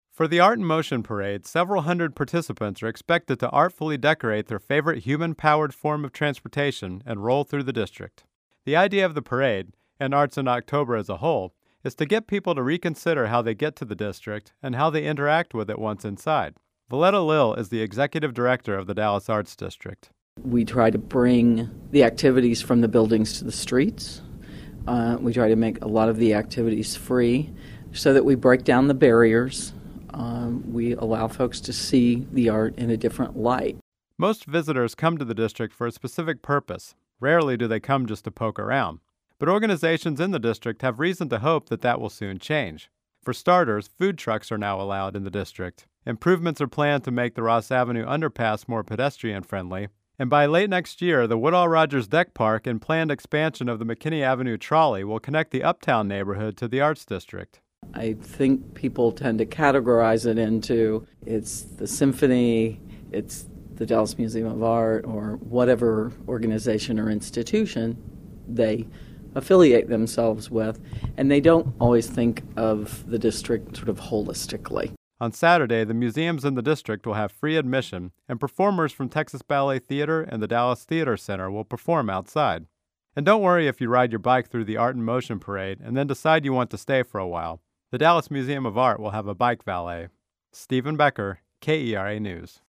• KERA Radio story: